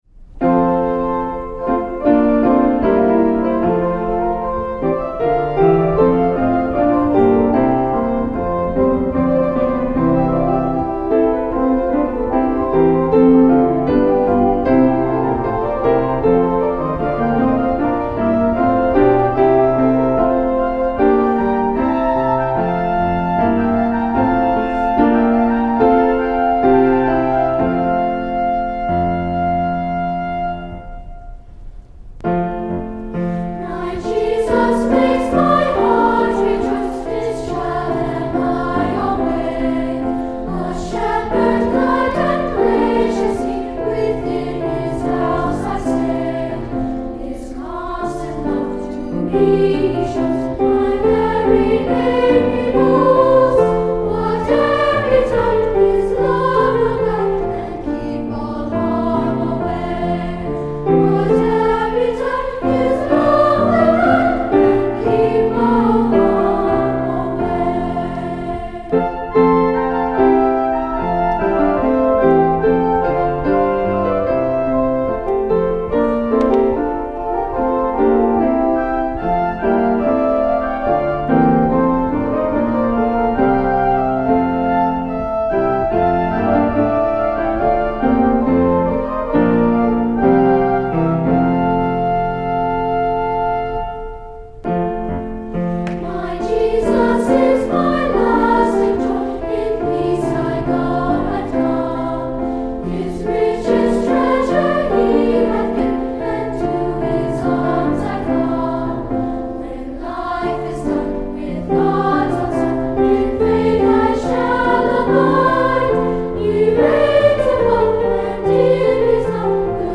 Voicing: Unison and Piano